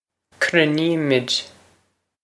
Pronunciation for how to say
krin-ee-mwid